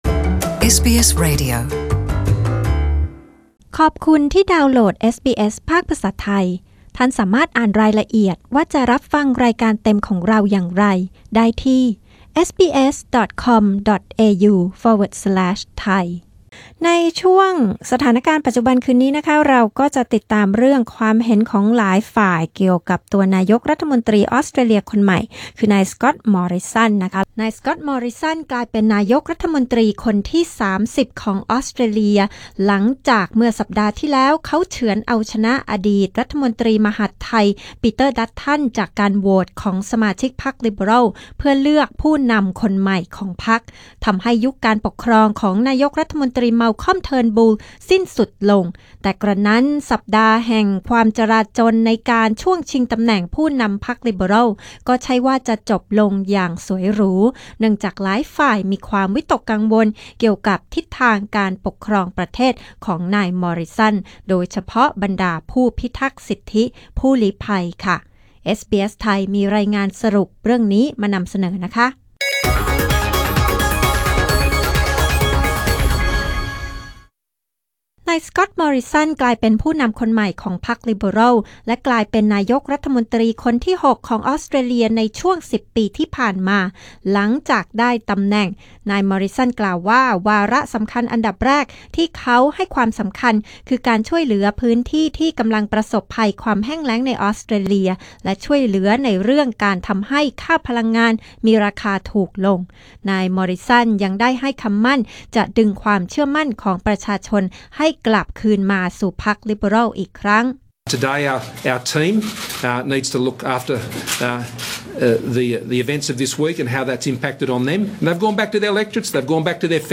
หลายฝ่ายคิดอย่างไรต่อการขึ้นเป็นนายกออสฯ คนที่ 30 ของสก็อตต์ มอร์ริสัน (พากย์ไทย)